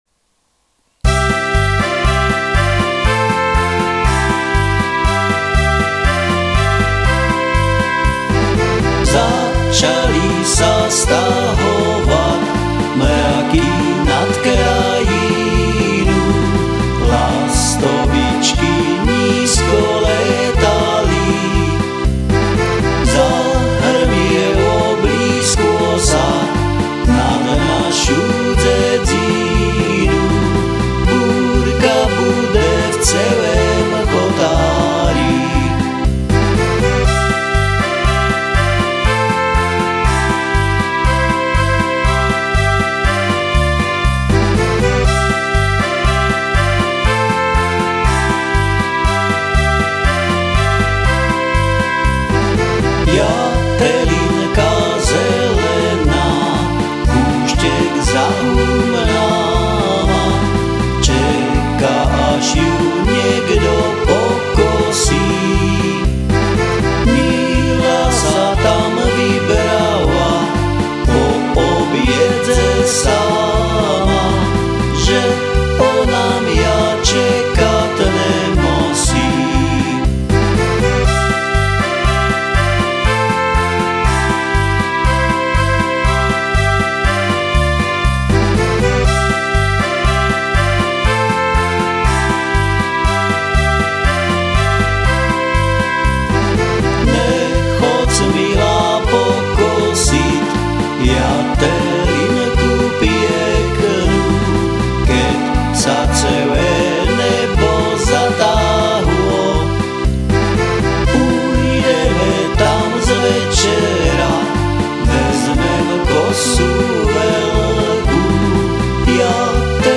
Záhorácke ľudové piesne - Piesne 4CD - Začali sa stahovat
Som amatérsky muzikant, skladám piesne väčšinou v "záhoráčtine" a tu ich budem prezentovať.